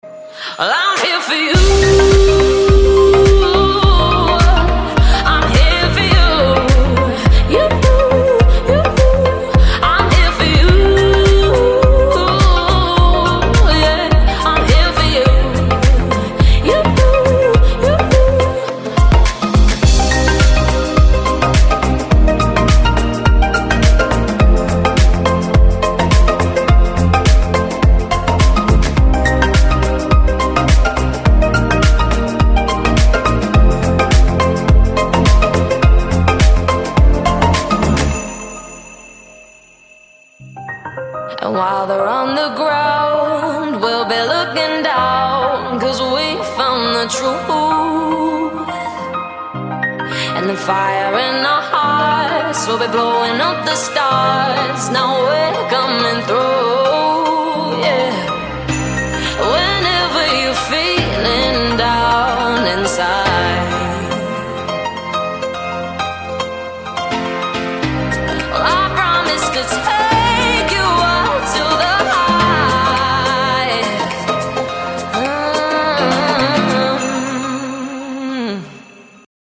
deep house
Жанры: Дип-хаус, Даунтемпо, Электроника.